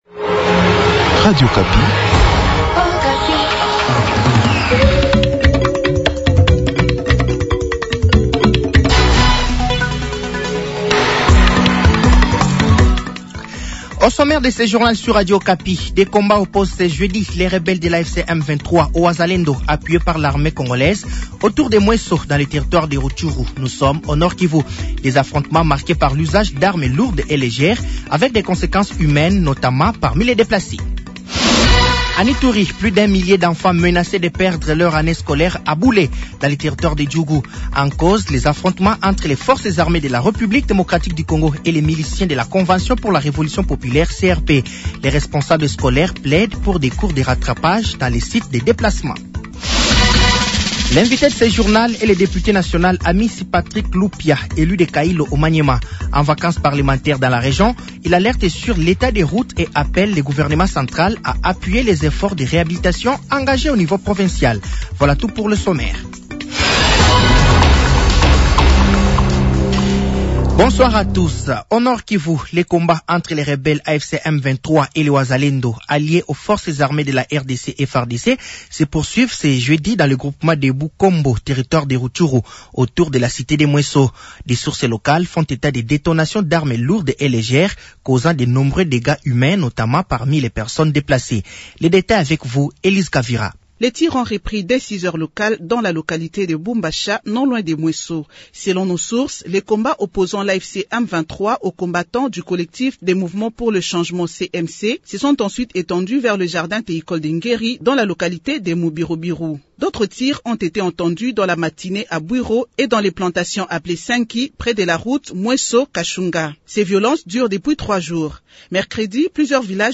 Journal français de 18h de ce jeudi 15 janvier 2026